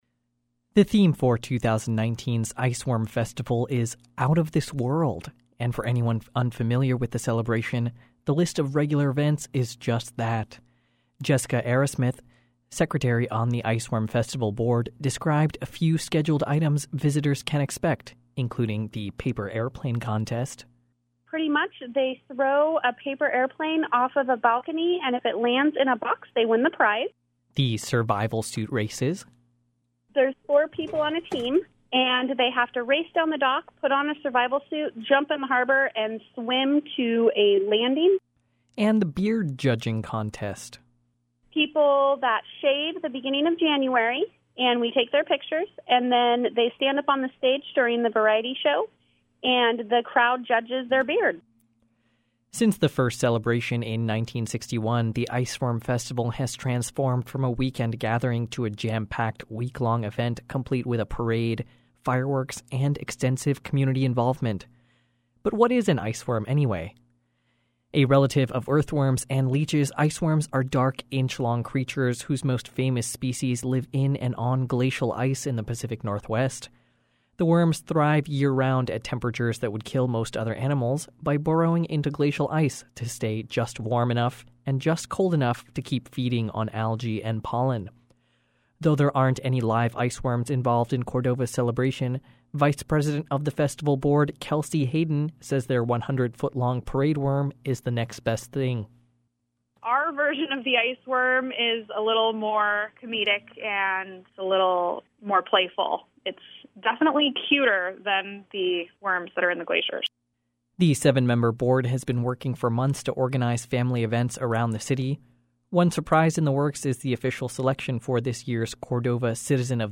CordovaIcewormFestival.mp3